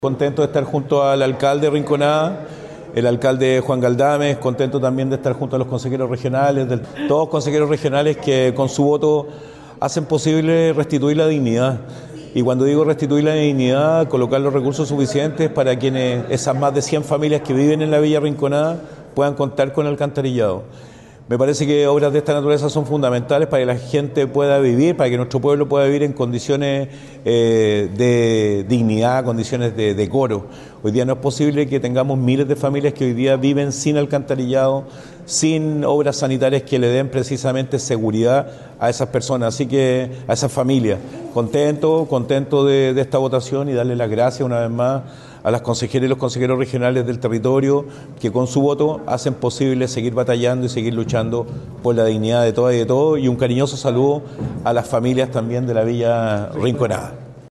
Por su parte, el Gobernador Regional Rodrigo Mundaca celebró la aprobación.